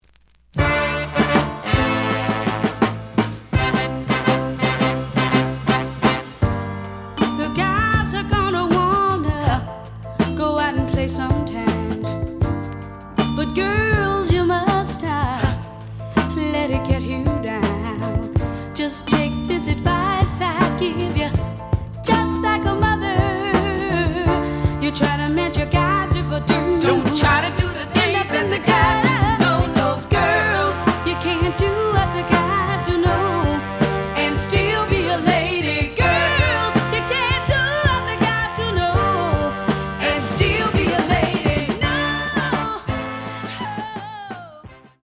The audio sample is recorded from the actual item.
●Genre: Soul, 70's Soul
Edge warp. But doesn't affect playing. Plays good.)